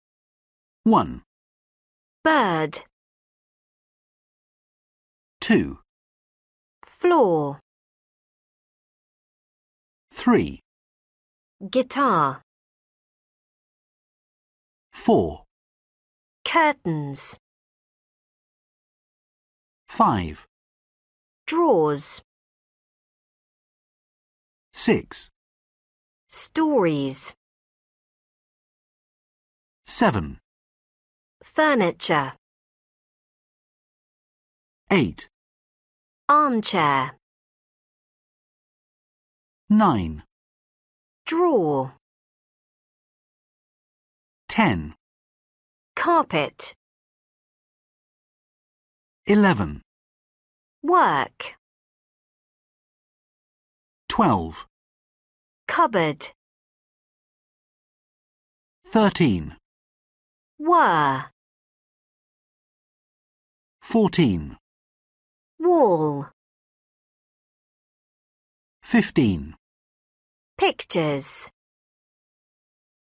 3  Listen and tick ✓ which sound you hear. Then, listen again and write the words. Some words do not have either sound.